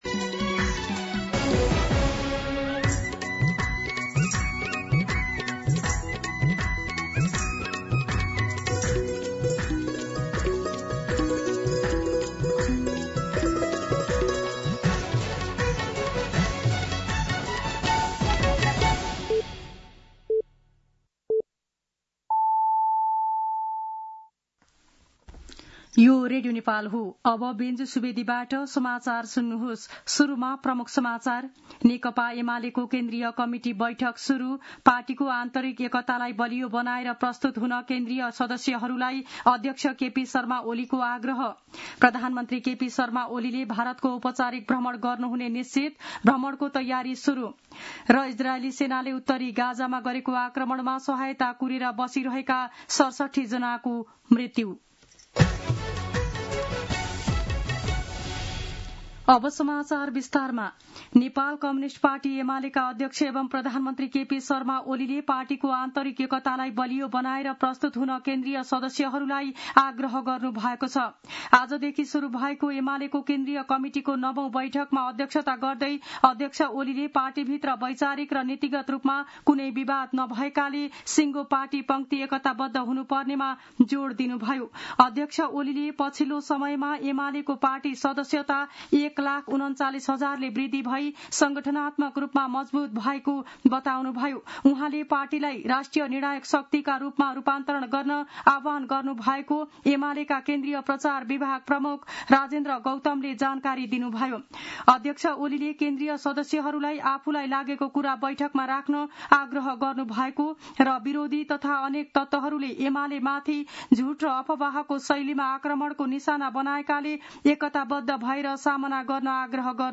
दिउँसो ३ बजेको नेपाली समाचार : ५ साउन , २०८२